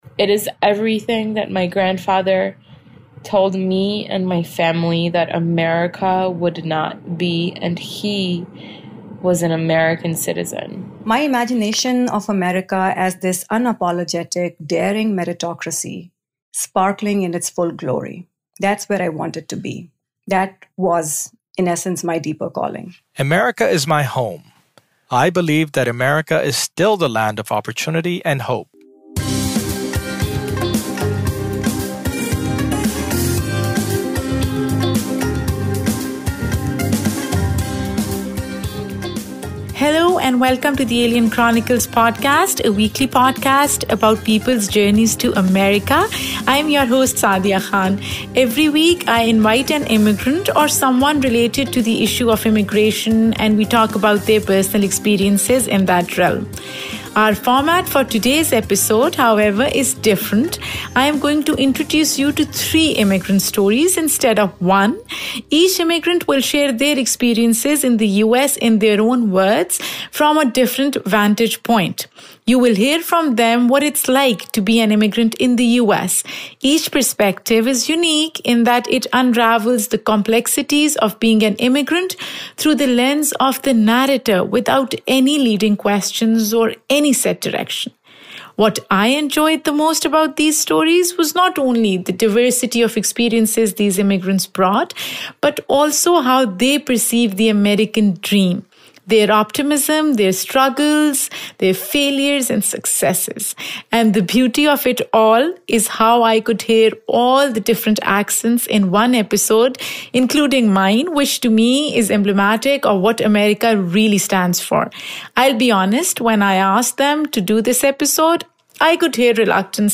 Immigrantly series presents the essence of immigrant experiences in the US. Each story is narrated by people who are at the heart of these compelling stories. Interviews in the series feature DACA recipients, writers, authors, activists, comedian, and others.